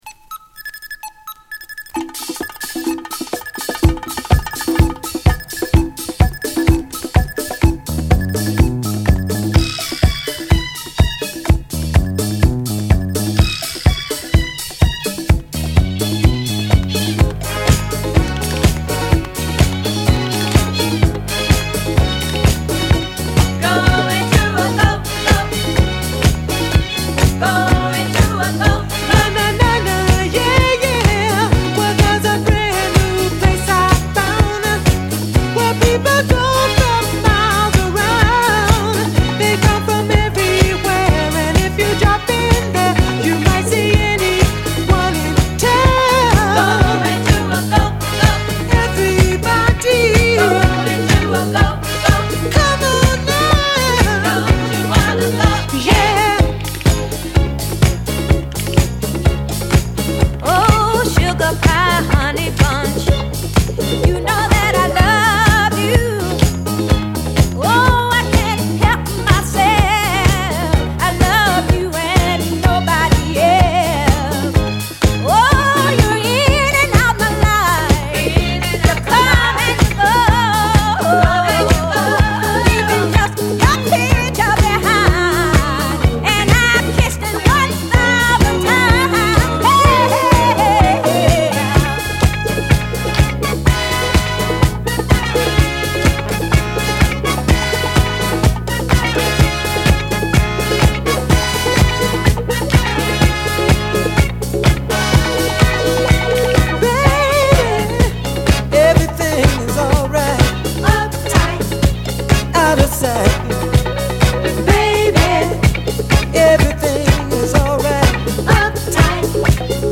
期待を裏切らないパーカッシヴなディスコトラックで
ストリングスとヴィブラフォンがキャッチーなメロディで絡むインスト曲